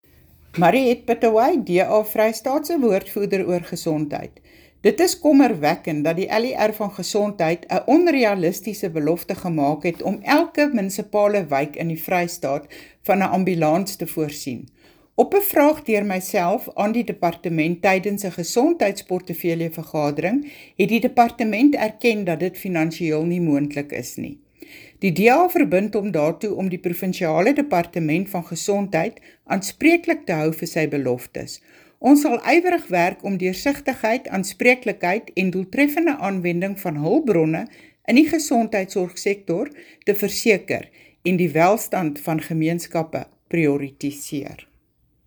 Issued by Mariette Pittaway MPL – DA Free State Spokesperson: Health & Education
Afrikaans soundbites by Mariette Pittaway MPL and